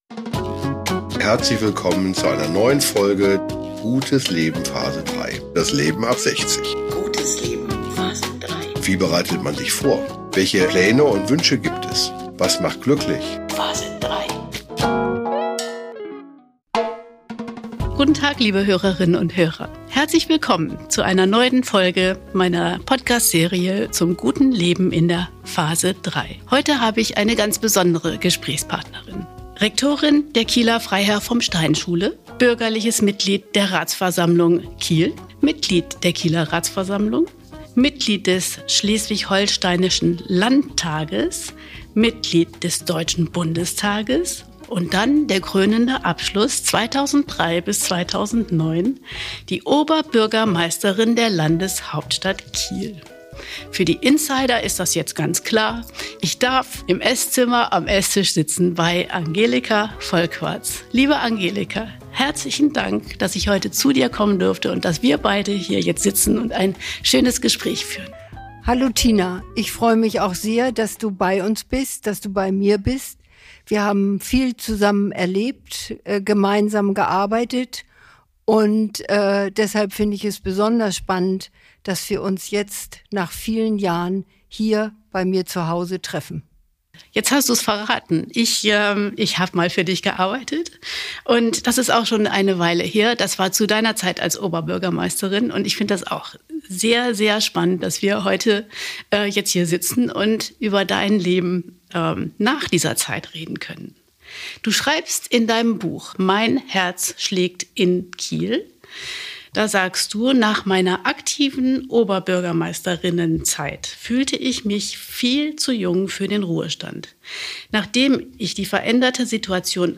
Wie macht man es, nach einem mehr als erfüllten und anstrengenden Leben als Politikerin und Oberbürgermeisterin der Stadt Kiel eine zufriedene und glückliche Phase3 zu erleben? In unserem wunderbaren Gespräch lässt uns Angelika einen Blick in ihr Leben werfen.